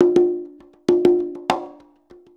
100 CONGAS06.wav